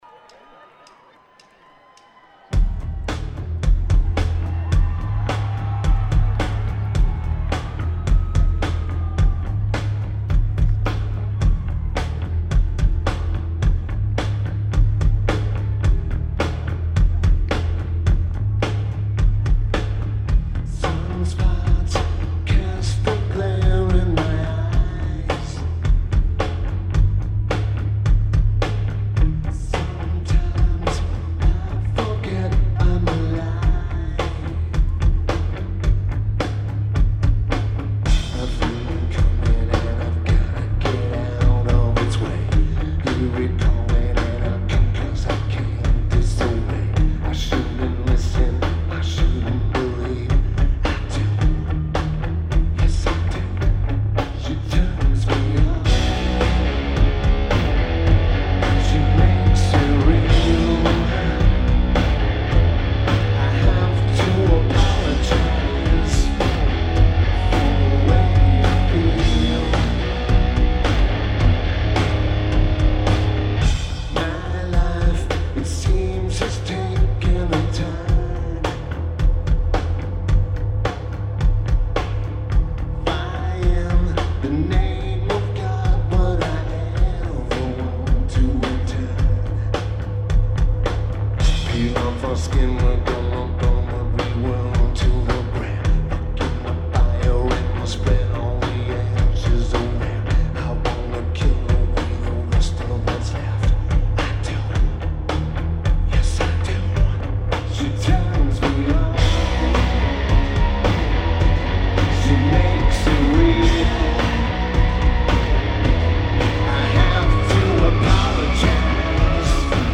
Lineage: Audio - AUD (Schoeps MK4s + Tinybox + Sony PCM-M10)